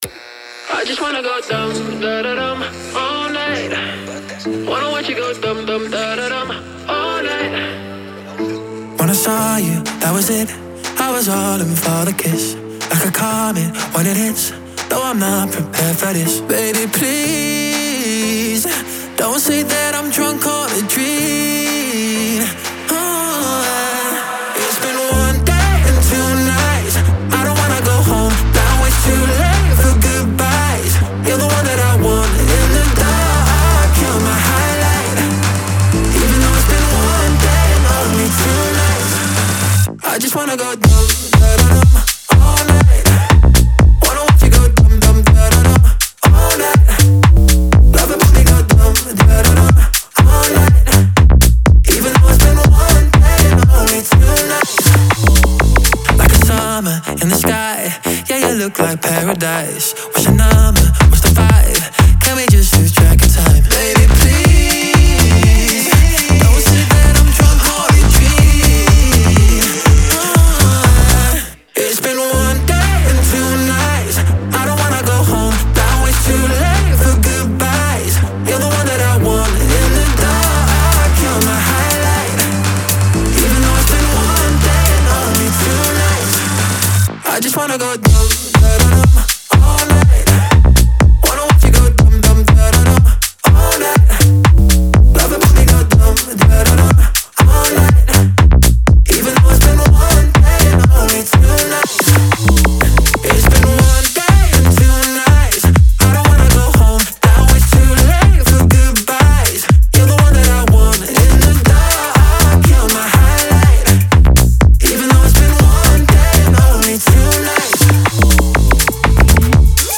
с его харизматичным вокалом